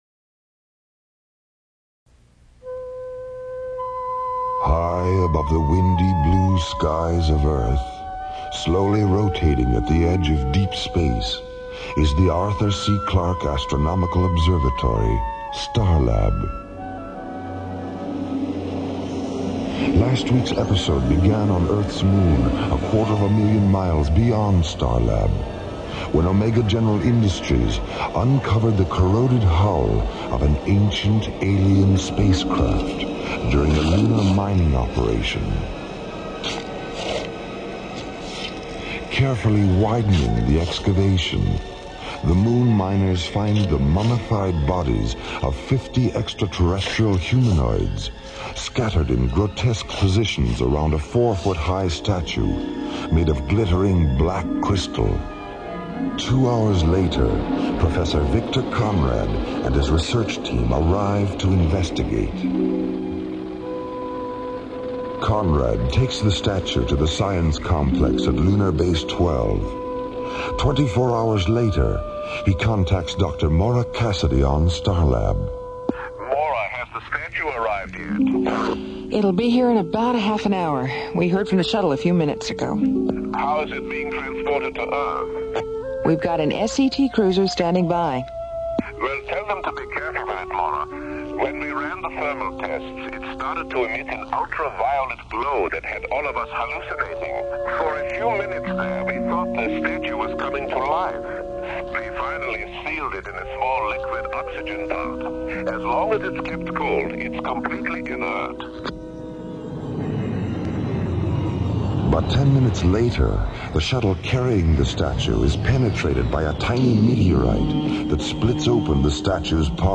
'Alien Worlds' was a syndicated radio show that brought together a blend of captivating narratives, realistic sound effects, and high production values, setting a new standard for audio drama.
The show was ahead of its time, utilizing a documentary style of dialogue that immersed listeners in its interstellar adventures. Each episode transported the audience to different corners of the galaxy, exploring complex themes and introducing memorable characters.